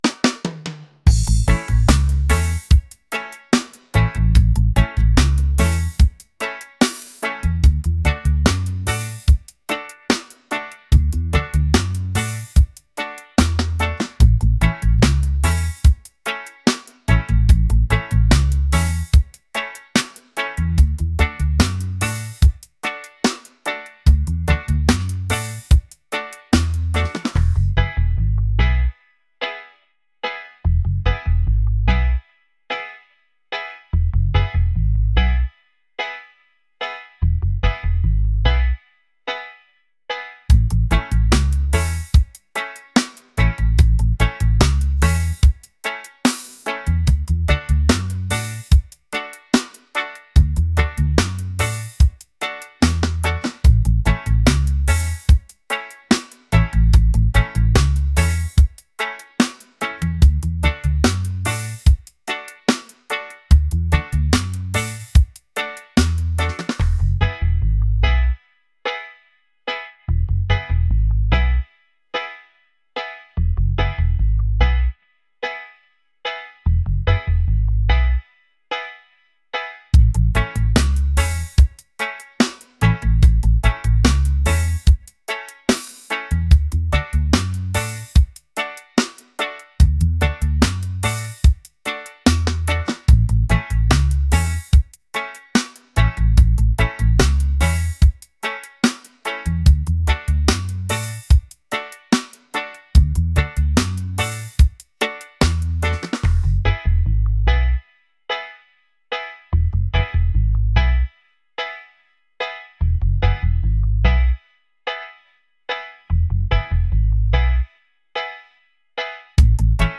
reggae | laid-back